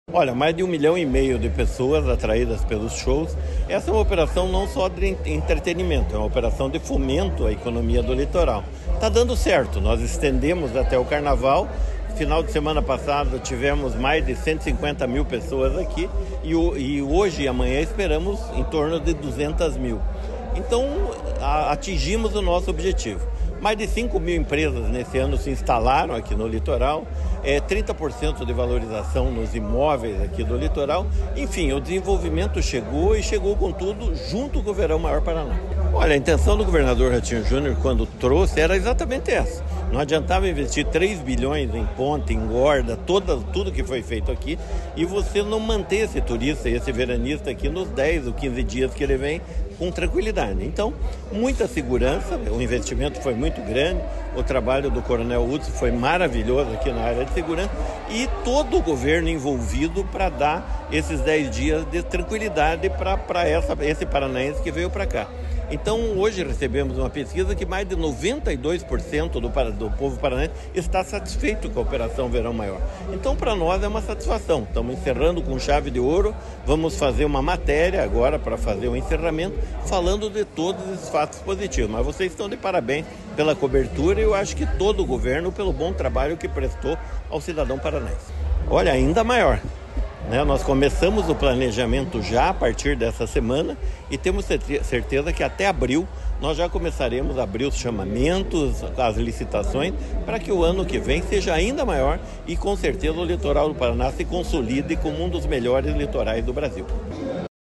Sonora do secretário de Esporte, Hélio Wirbiski, sobre os bons resultados econômicos do Verão Maior Paraná